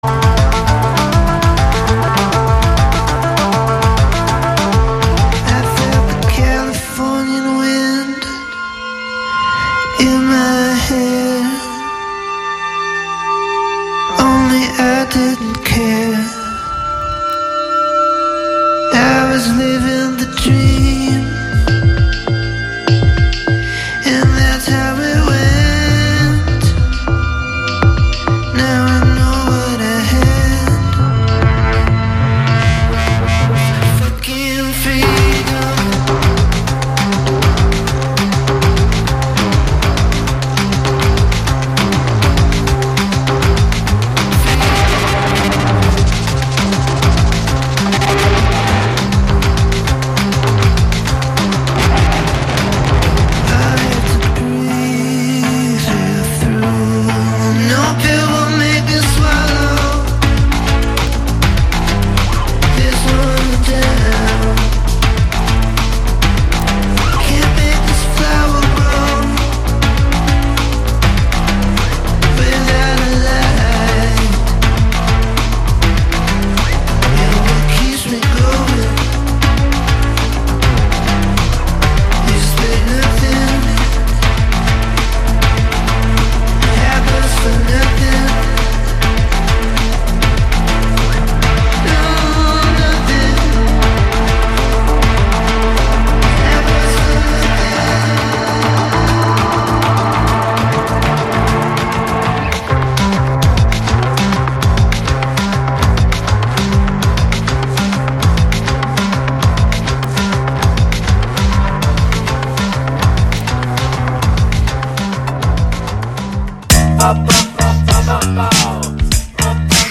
Vos esgourdes seront abreuvées de 50’s Rockabilly